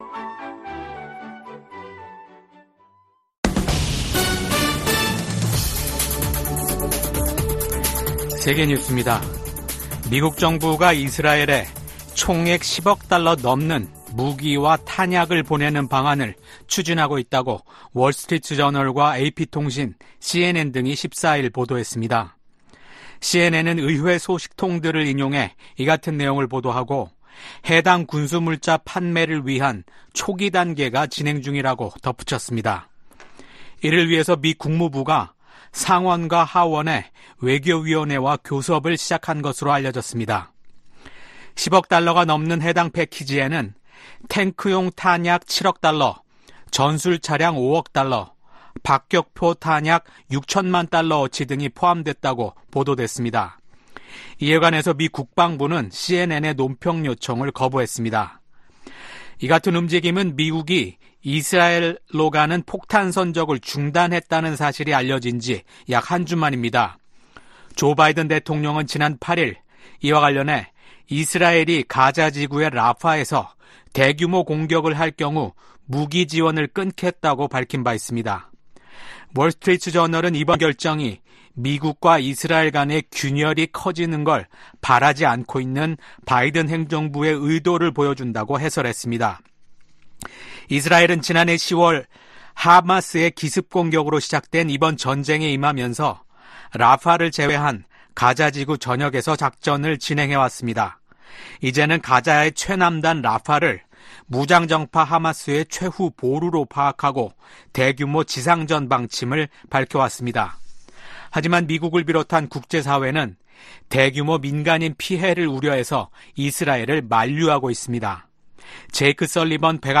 VOA 한국어 아침 뉴스 프로그램 '워싱턴 뉴스 광장' 2024년 5월 16일 방송입니다. 우크라이나를 방문 중인 토니 블링컨 미 국무장관이 14일 러시아의 침략 전쟁과 이를 지원하는 북한, 이란을 비판했습니다. 북한의 미사일 도발 가속화로 인해 안보 태세를 강화하는 것 외에는 다른 선택지가 없다고 국무부 동아태 담당 차관보가 말했습니다.